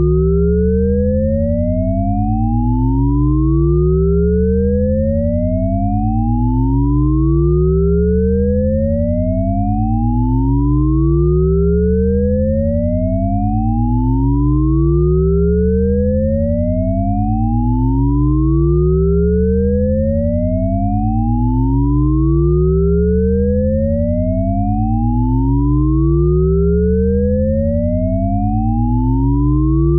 son_montant_indefiniment à écouter